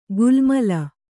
♪ gulmala